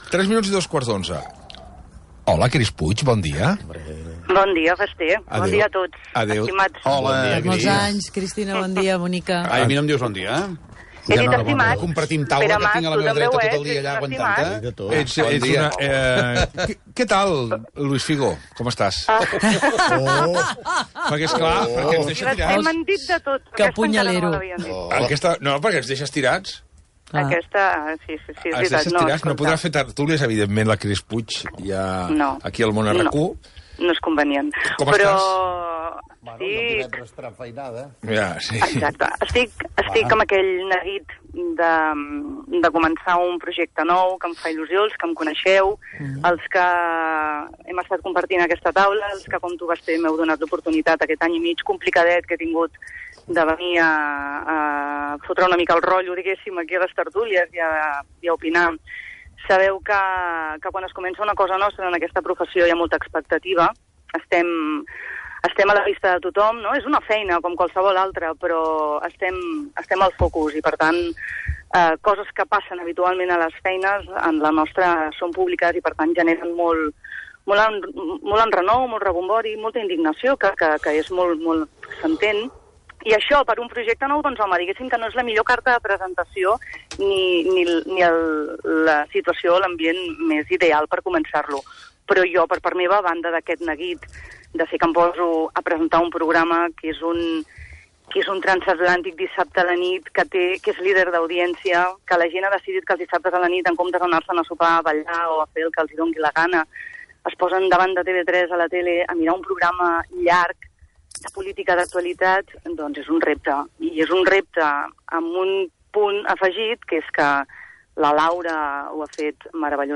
tertúlia
Info-entreteniment